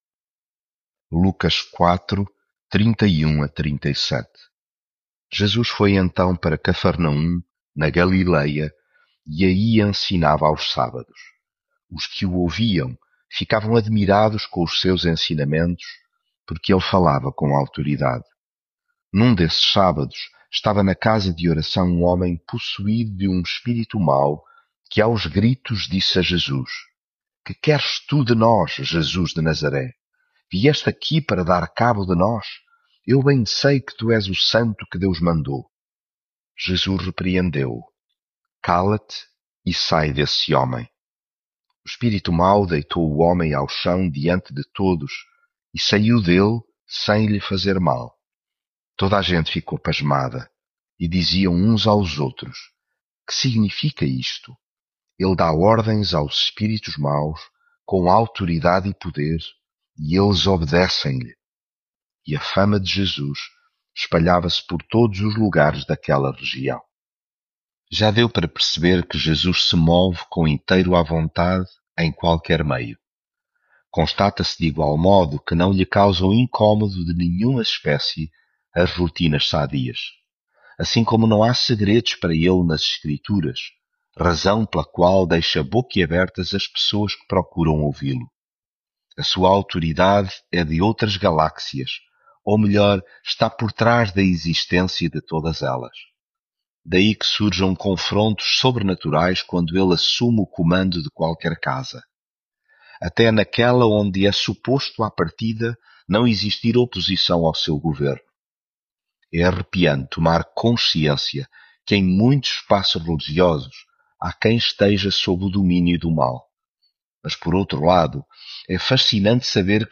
devocional Lucas leitura bíblica Foi então para Cafarnaum, na Galileia, e aí ensinava aos sábados .
Devocional 22 com paixão